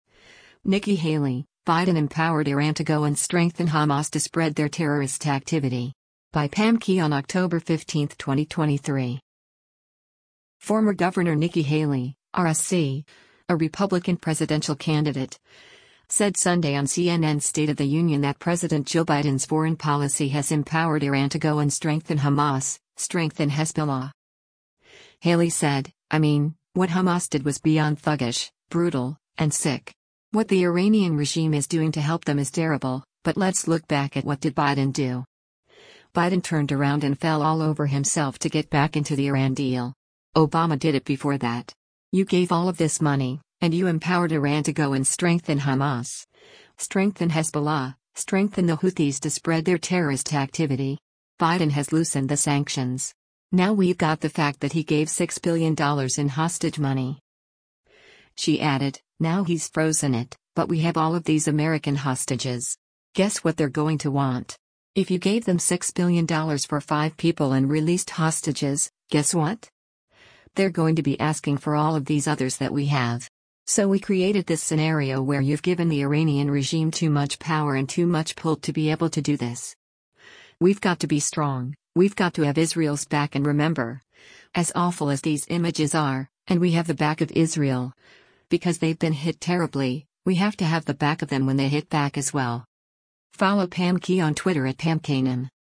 Former Gov. Nikki Haley (R-SC), a Republican presidential candidate, said Sunday on CNN’s “State of the Union” that President Joe Biden’s foreign policy has “empowered Iran to go and strengthen Hamas, strengthen Hezbollah.”